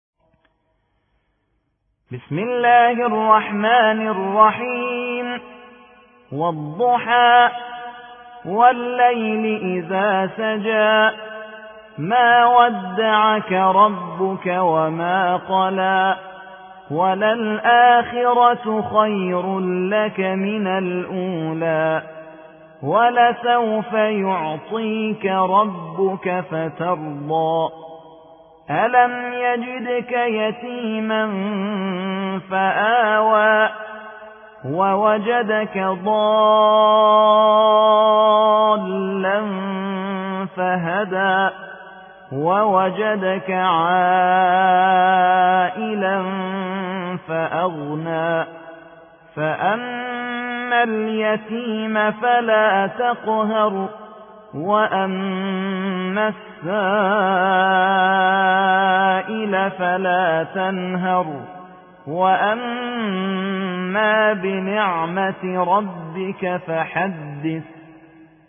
93. سورة الضحى / القارئ